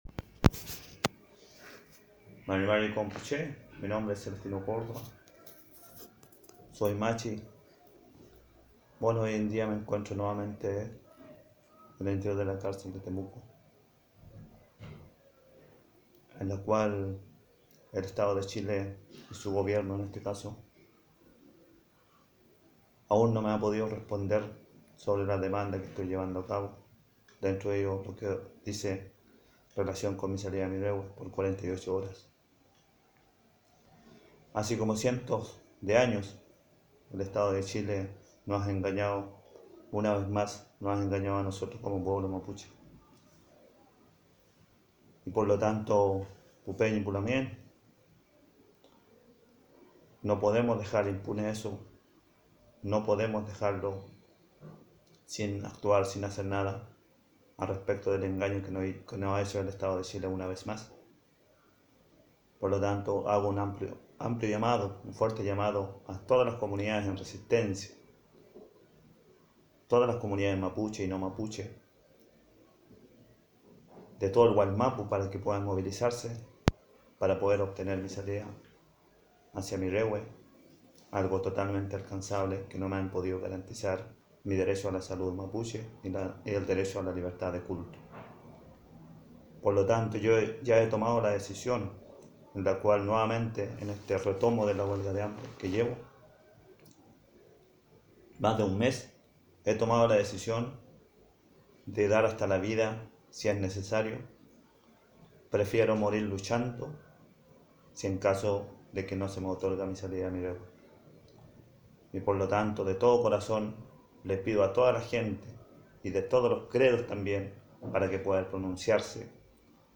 04/07/2018.-  Por Mapuexpress.- La autoridad ancestral y espiritual,  Machi Celestino Córdova, quien se encuentra en huelga de hambre reiniciada a fines de mayo tras la negativa de gendarmería y el gobierno de su salida al rewe por 48 horas, desde la Cárcel de Temuco, envía un audio donde solicita el apoyo, solidaridad y movilizaciones de la comunidad Mapuche y no Mapuche.